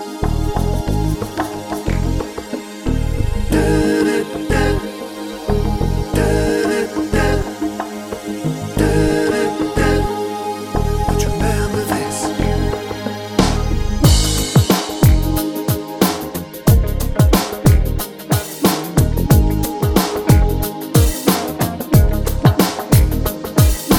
Long Version One Semitone Down Pop (1990s) 6:23 Buy £1.50